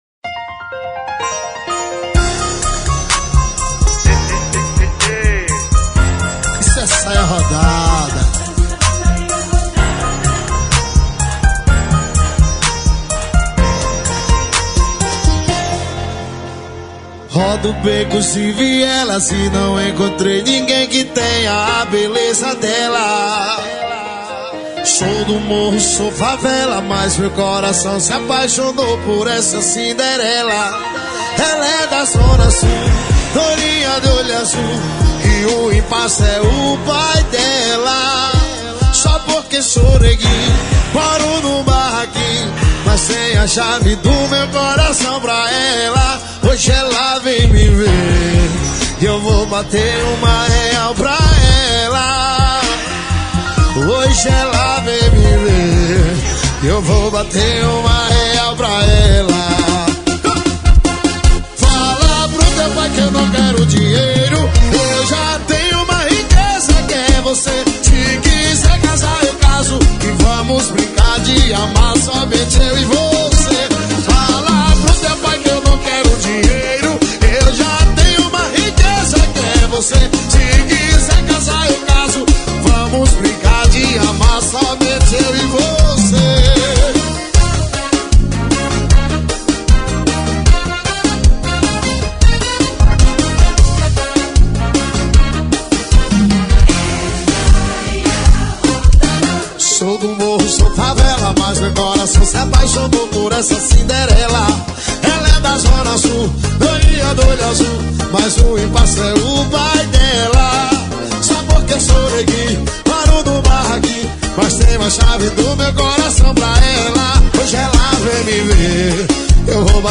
Brega e Forro